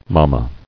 [ma·ma]